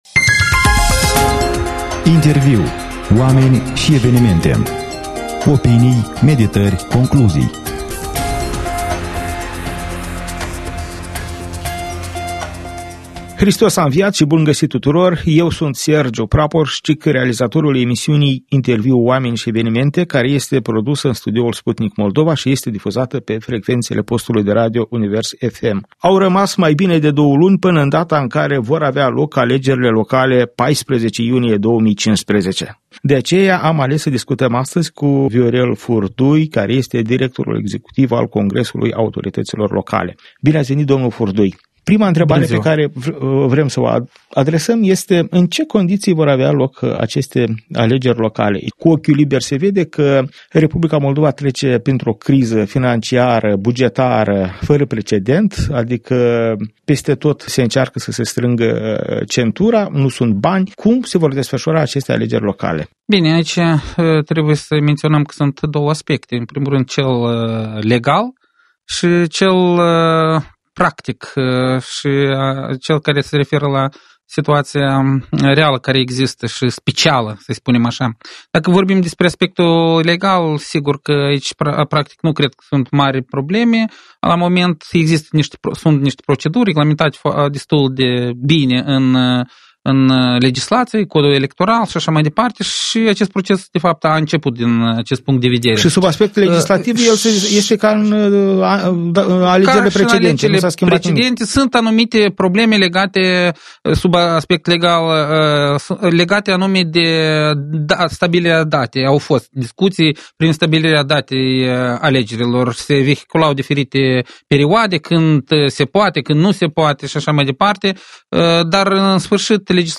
Interviu: Oameni și Evenimente. Alegeri locale pentru o administrație nereformată